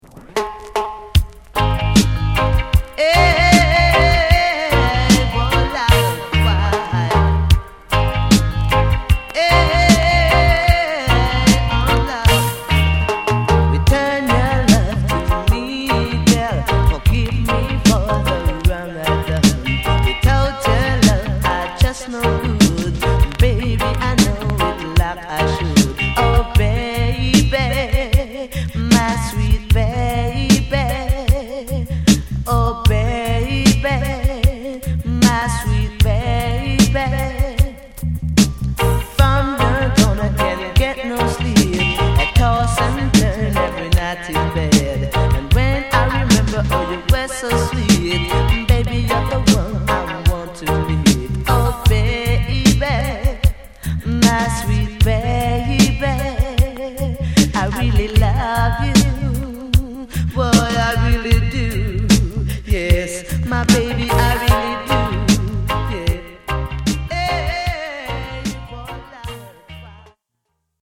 Jamaica